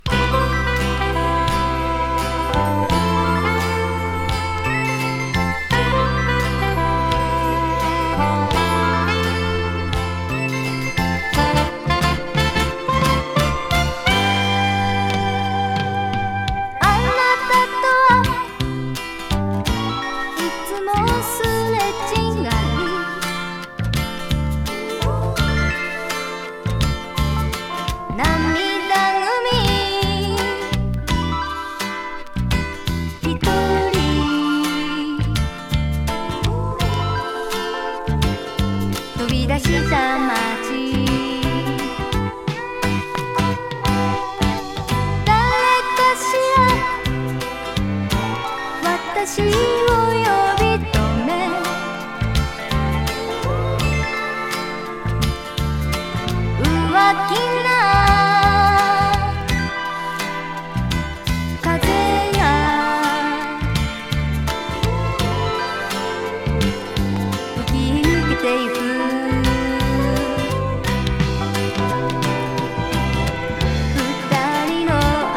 ミディアムテンポのメロウチューン